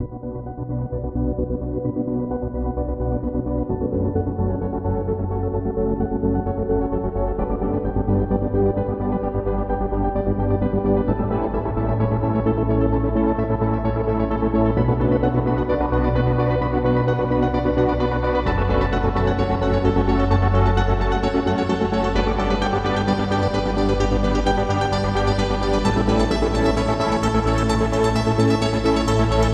Trance Piano Lift Up 130 Bpm
Tag: 130 bpm Trance Loops Piano Loops 4.97 MB wav Key : Unknown